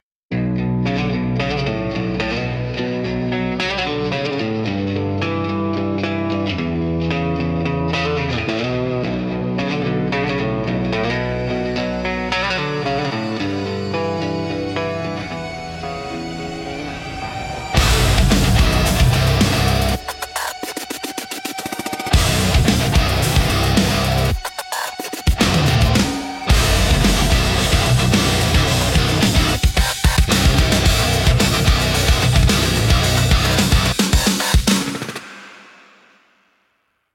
Instrumental - The Devil’s Half-Step Bend